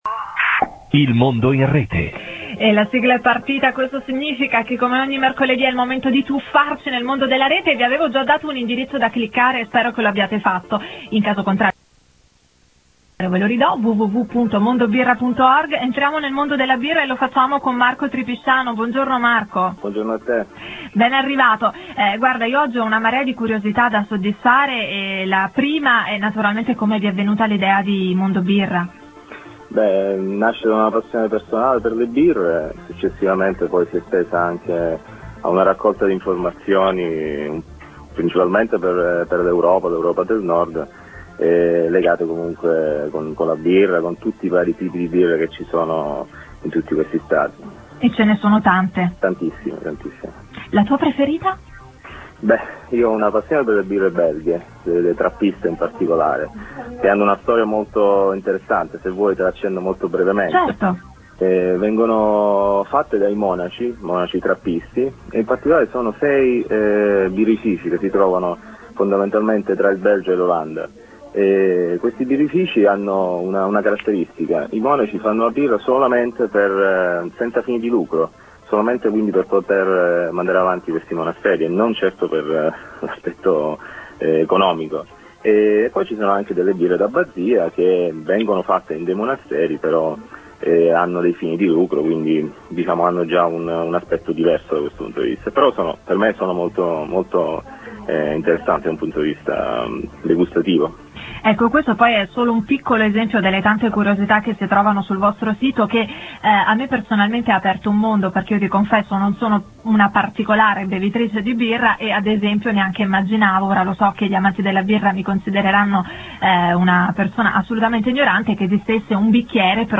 Intervista radiofonica - 16 Marzo 2005